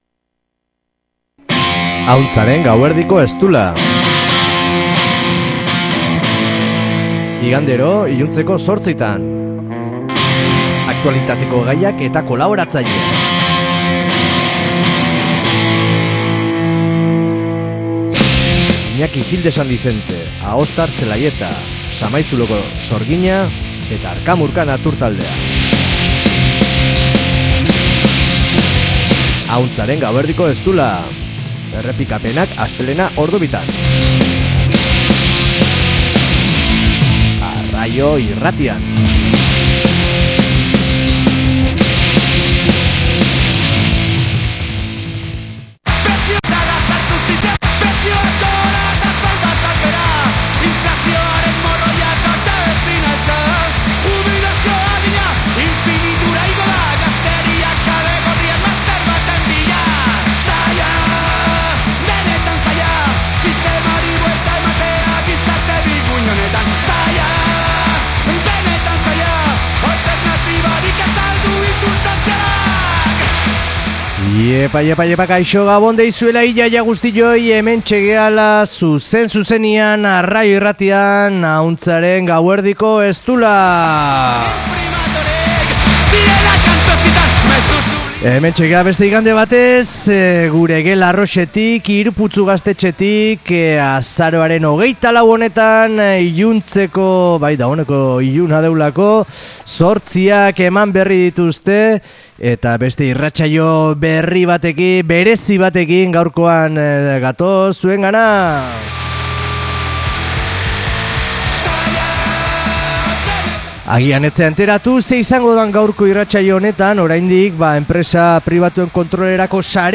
Munduko poeta emakumezkoak: errezitaldi bat